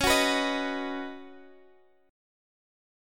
Db7sus2sus4 Chord
Listen to Db7sus2sus4 strummed